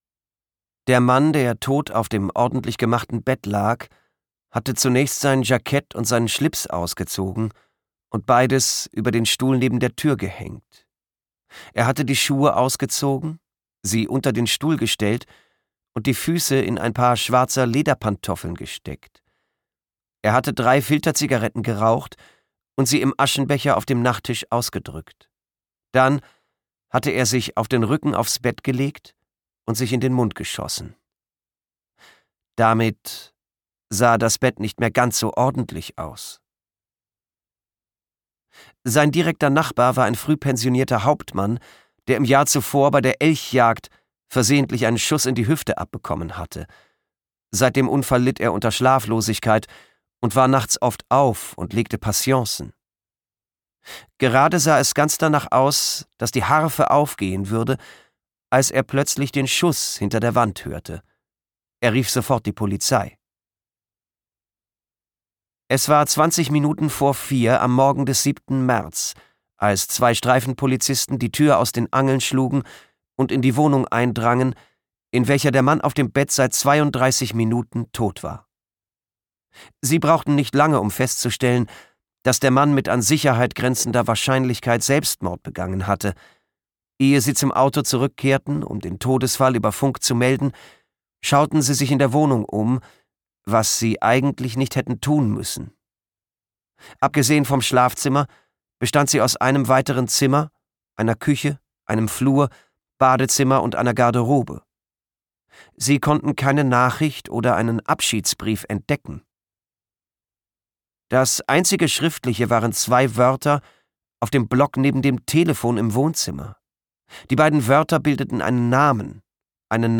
Schweden-Krimi
Gekürzt Autorisierte, d.h. von Autor:innen und / oder Verlagen freigegebene, bearbeitete Fassung.